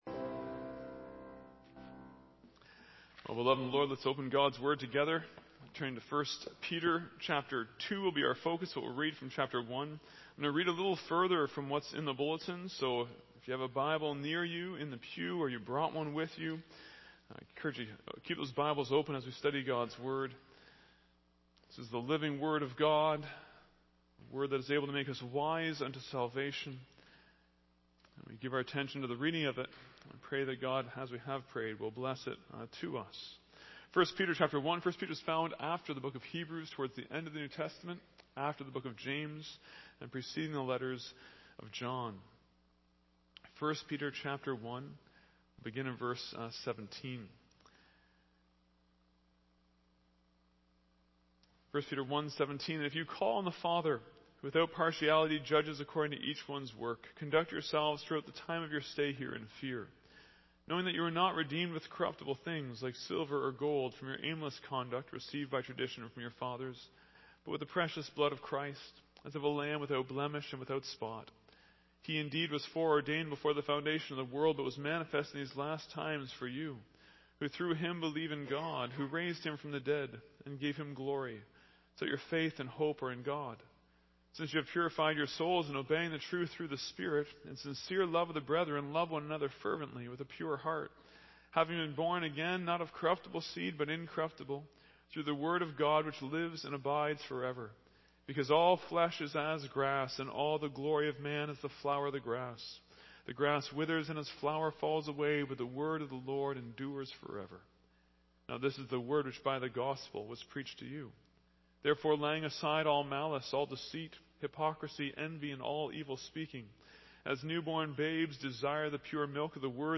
Non-Series Sermon